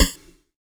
SNARE 35  -R.wav